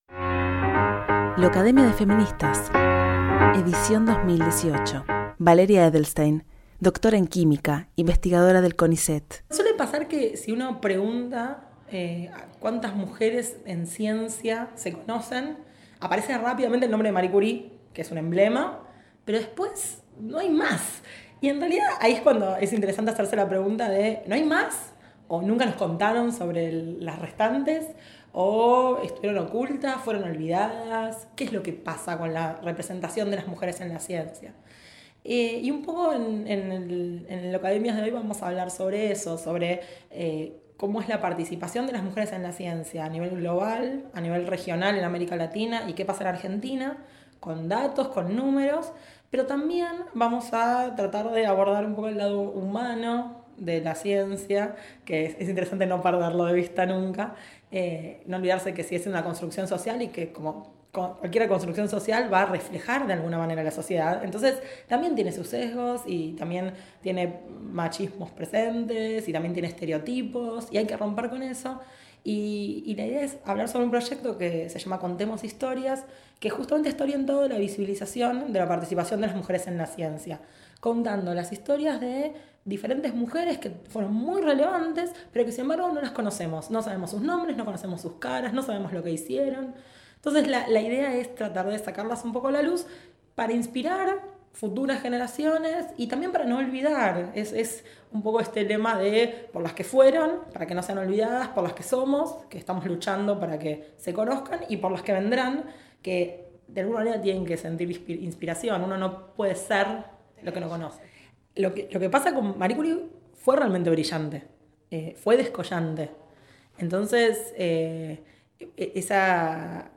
El primer encuentro de Locademia de feministas Edición 2018
una de las tres oradoras que disertaron respecto al modo en que los aportes de las mujeres en la ciencia, la política, el deporte o el arte muchas veces quedan fuera del registro de los acontecimientos.